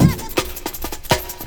Think3 Breakbeat 32k